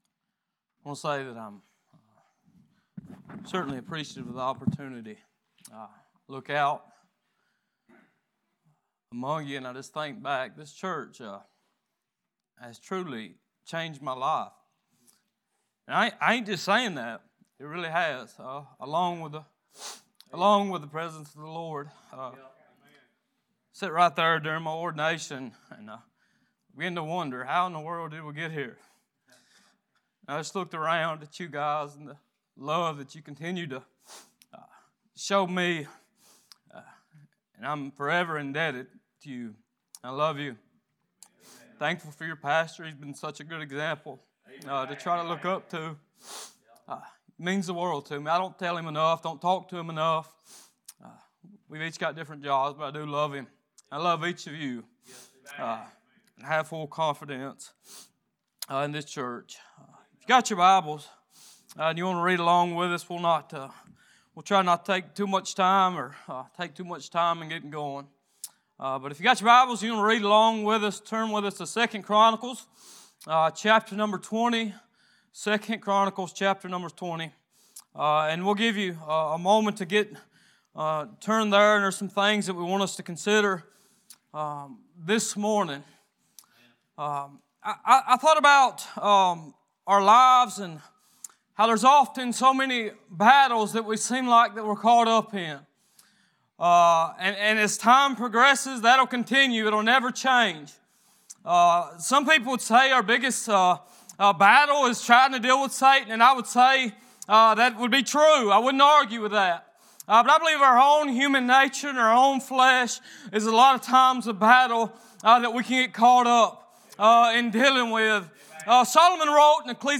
Sunday Morning Passage: 2 Chronicles 20:3-13, James 4:7 Service Type: Worship « Are You Prepared For The Fire?